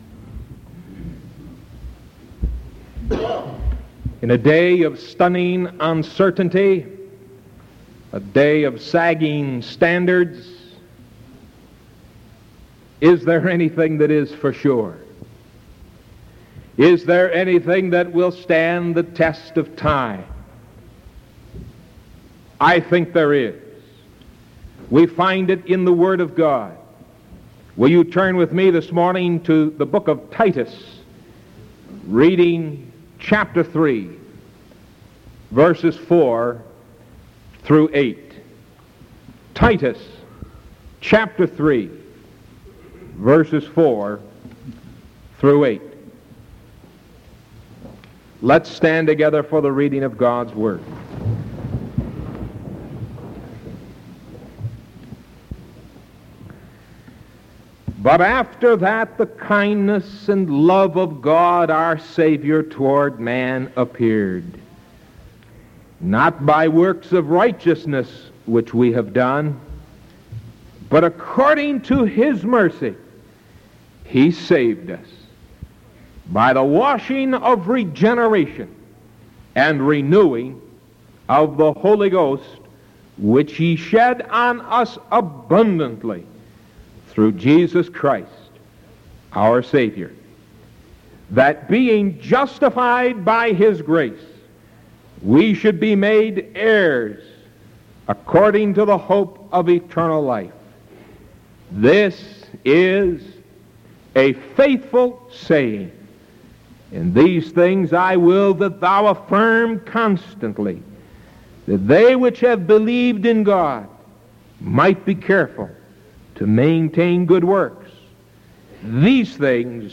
Sermon July 8th 1973 AM